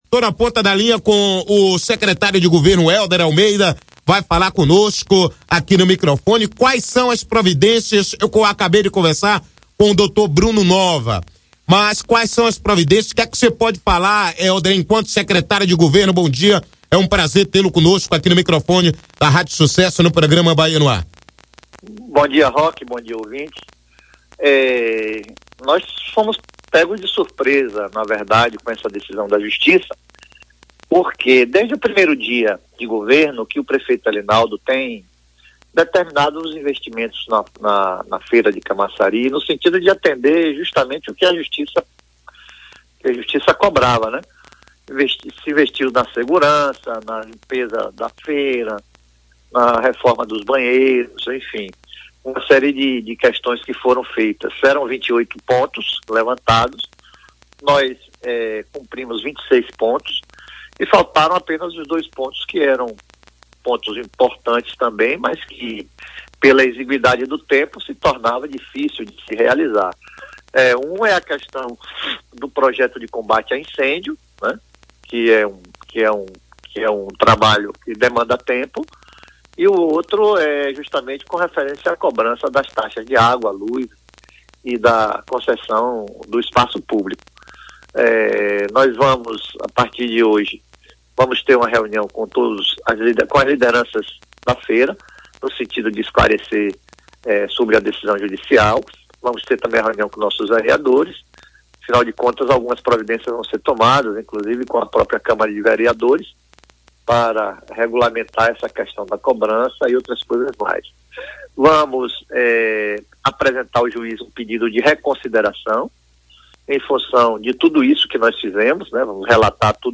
Confira a entrevista completa com o secretário Helder Almeida.
bahianoar_entrevista-exclusiva-com-o-secretario-de-governo-de-camacari-helder-almeida..mp3